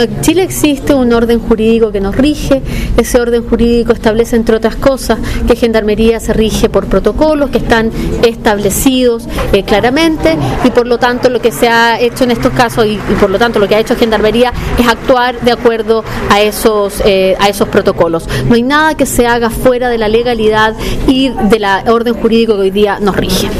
La Ministra Vocera de Gobierno, Paula Narváez dijo que respecto a ese tema, en Chile existen protocolos legales establecidos por ley, los cuales personal de gendarmería debe aplicar en situaciones como la ocurrida.